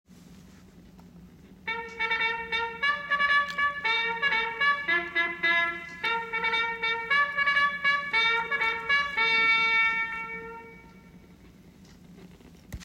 原曲は陸軍の「食事」喇叭譜で、製品パッケージに描かれているラッパのマークを想起させるメロディとして採用された。